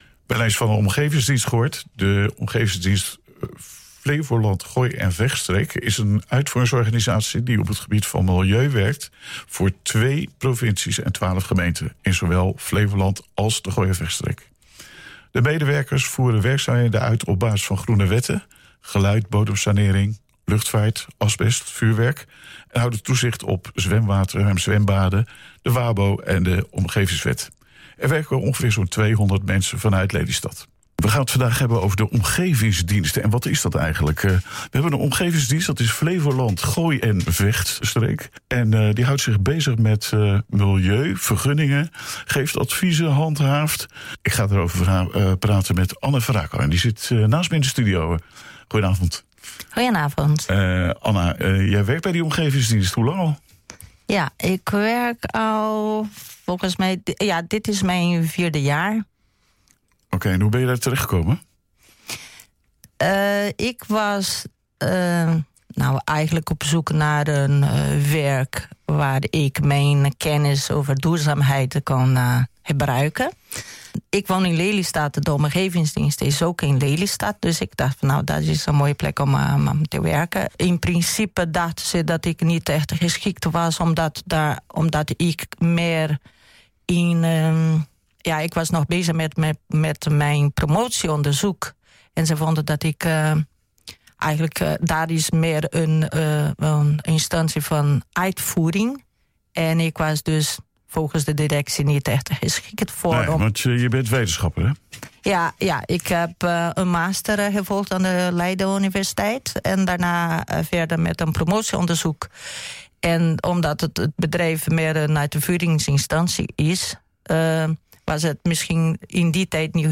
Voor tienen liep ze er al rond te banjeren, cultuurwethouder Karin van Werven, op de Huizer Dag, midden tussen haar cultuurleveranciers.